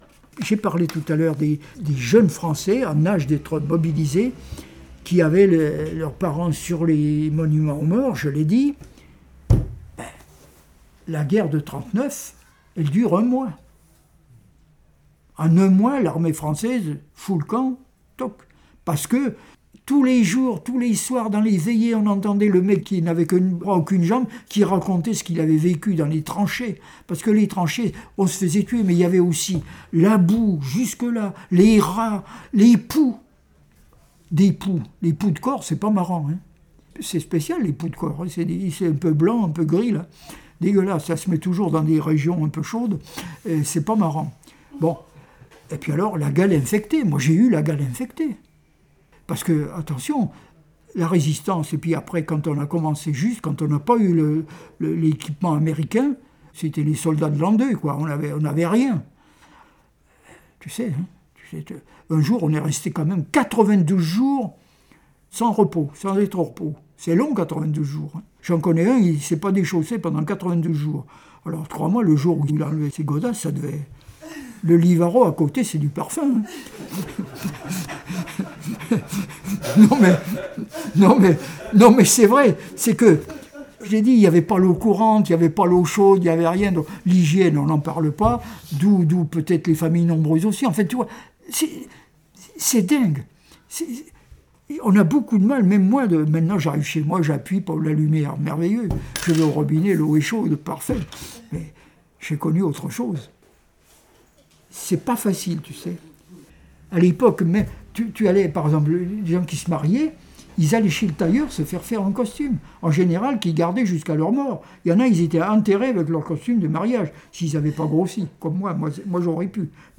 Suite aux questions du public